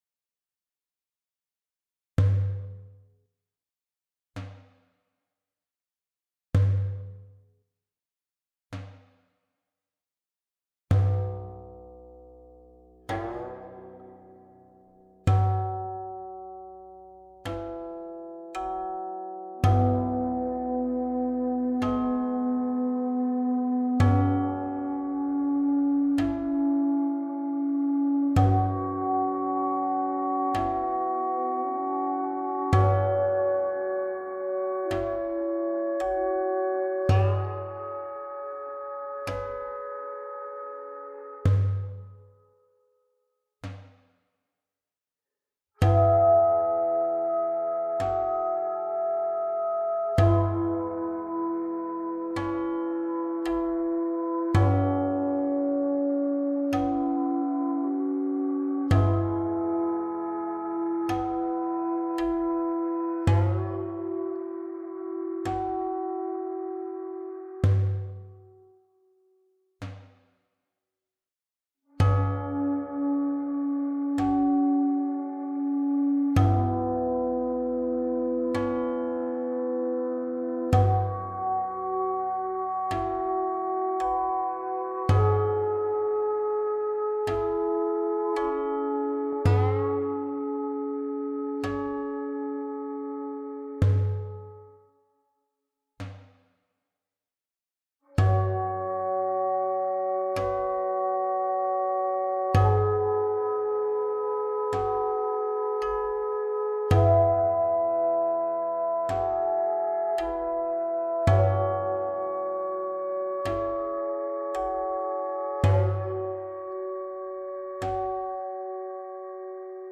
黃鐘徵 · 徵調式（取250.56Hz為黃鐘）
黃鐘起調 · 清黃鐘畢曲
由於我手裡的樂器不齊，目前無法真正做出典禮樂的恢洪氣勢，所以，只能求其神而取其意。
為了給後續的樂曲留出氣勢逐漸洪大的發展空間，這首曲子的旋律部分僅僅用了古琴和簫，打擊樂部分，用了波斯Daff鼓和銅鈴。其中，簫採用了二聲部重奏來演繹寬廣的空間；古琴的泛音勉強替代了鐘磬；Daff鼓用來代替傳統的大鼓和小鼓。古琴的散音淡淡的為樂曲鋪墊了厚重感，以試圖彌補古琴泛音和Daff鼓的輕薄音色之不足。
這套樂曲使用東方的五度相生律（三分損益法生律），取250.56Hz為黃鐘，這相當於A4=422.82Hz。